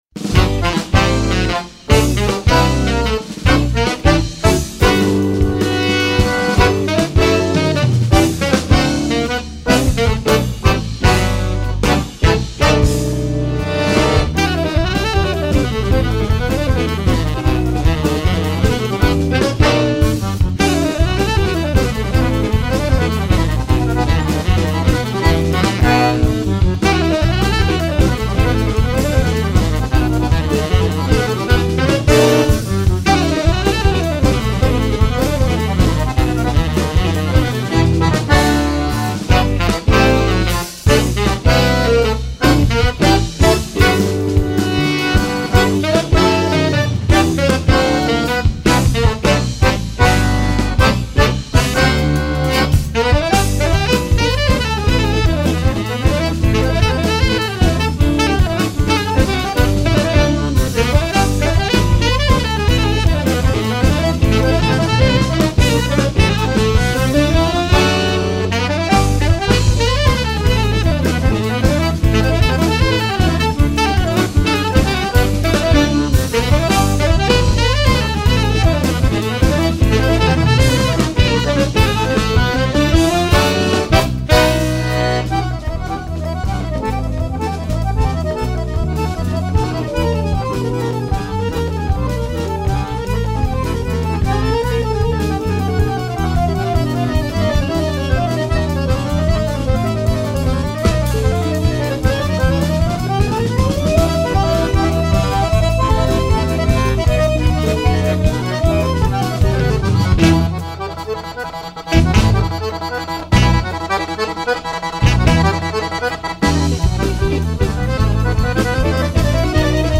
2806   03:35:00   Faixa: 4    Jazz
Bateria, Percussão
Baixo Elétrico 6, Violao Acústico 6
Guitarra, Viola
Acoordeon